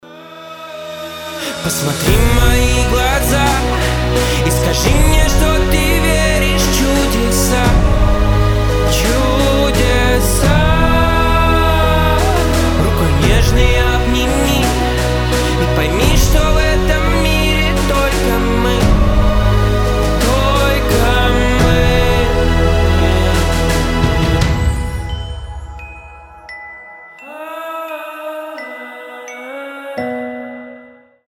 • Качество: 320, Stereo
мужской голос
спокойные
медленные